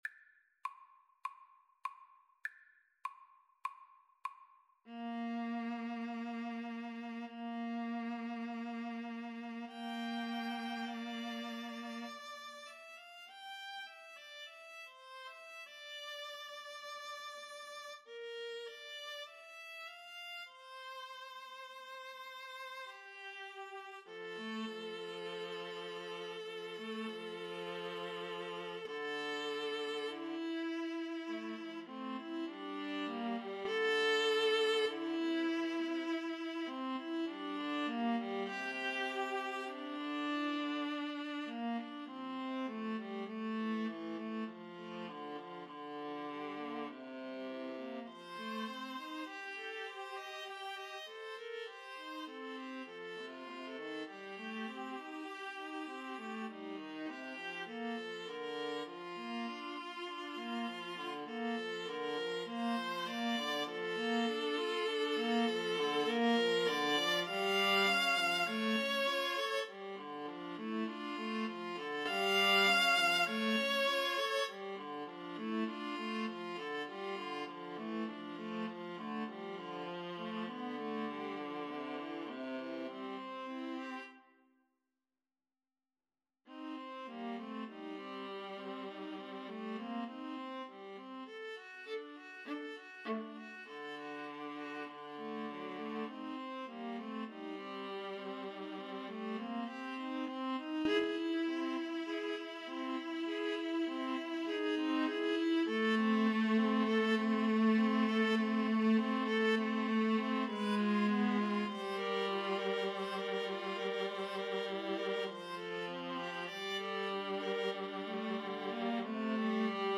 4/4 (View more 4/4 Music)
Andantino sans lenteur (View more music marked Andantino)
Classical (View more Classical Viola Trio Music)